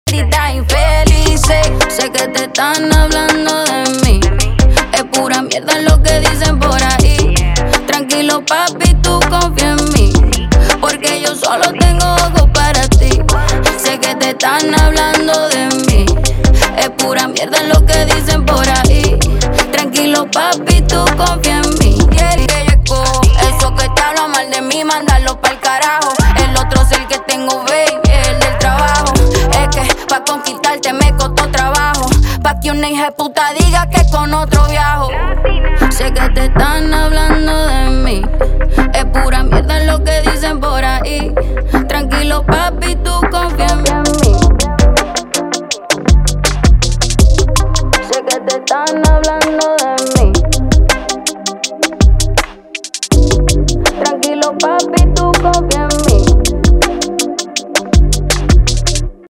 • Качество: 320, Stereo
поп
спокойные
красивый женский голос
Испанский поп